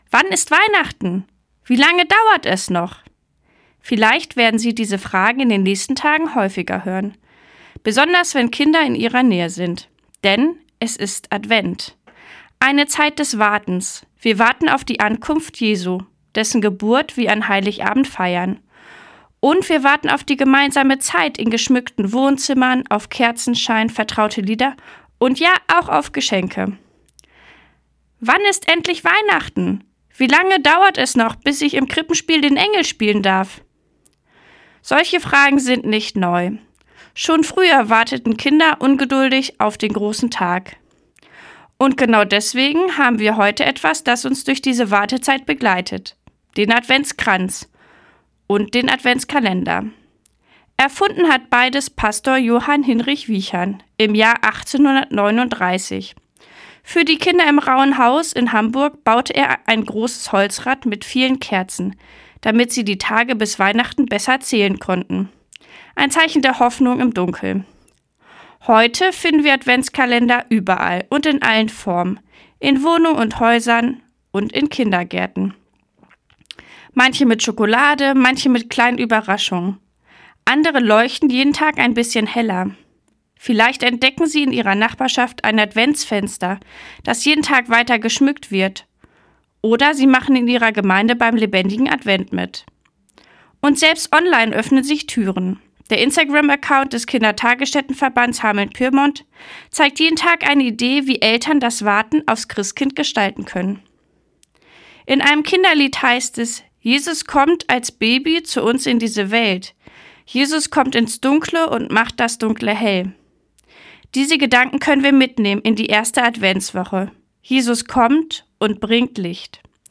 Radioandacht vom 1. Dezember